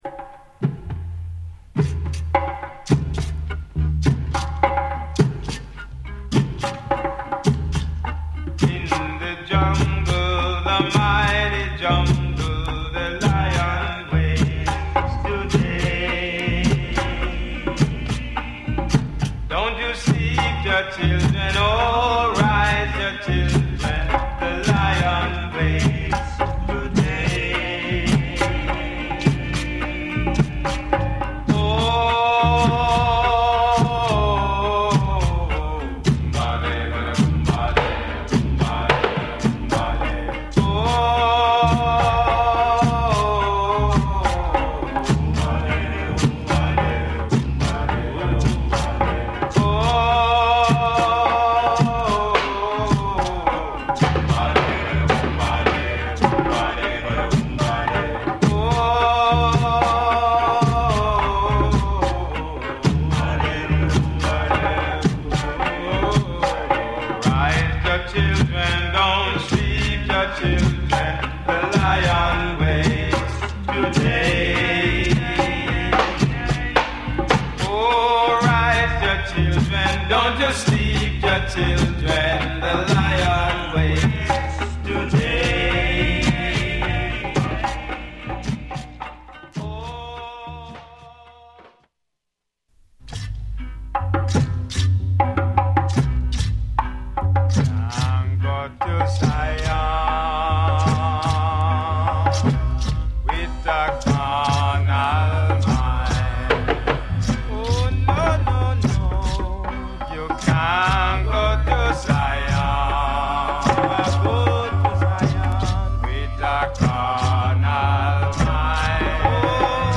一切の装飾を外したナイヤビンギの本当の姿がここにある…。